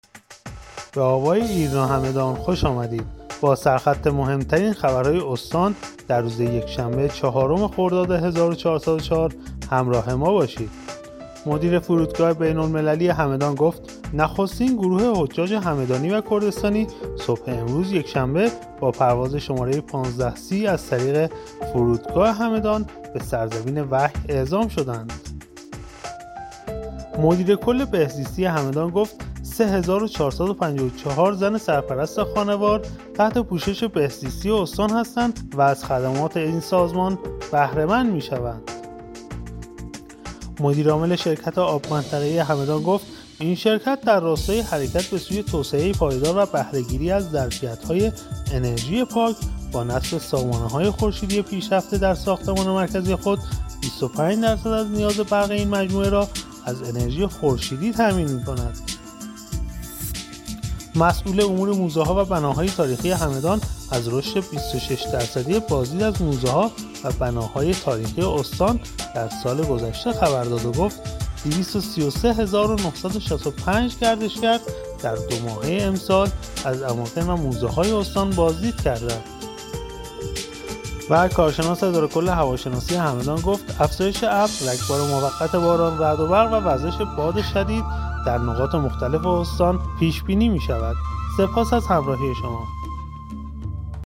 همدان-ایرنا- مهم‌ترین عناوین خبری دیار هگمتانه را هر شب از بسته خبر صوتی آوای ایرنا همدان دنبال کنید.